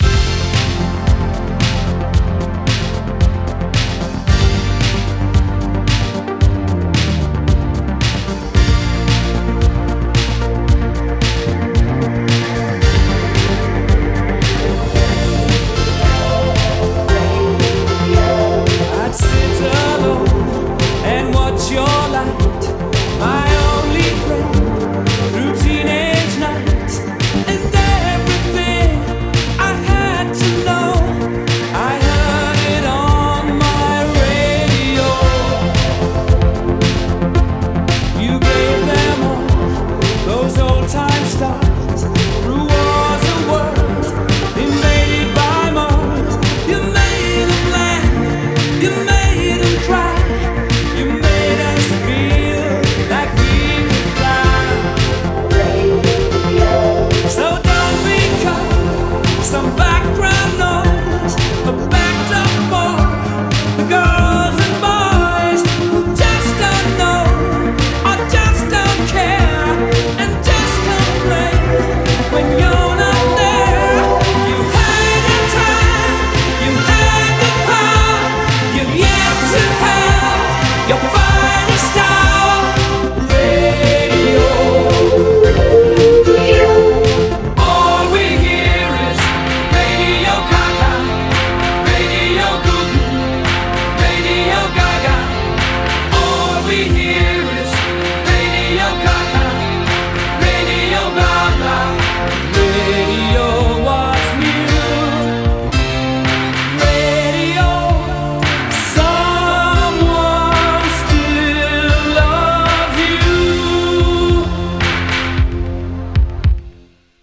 BPM111-114
Audio QualityMusic Cut